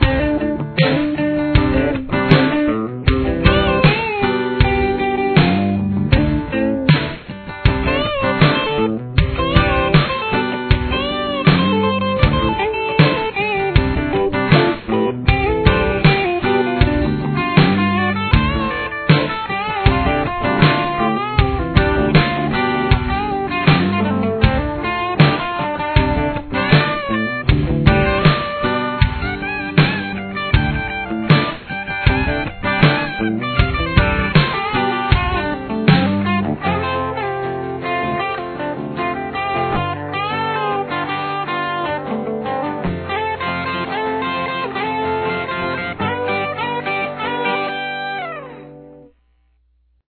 Guitar Solo
Here is the full solo: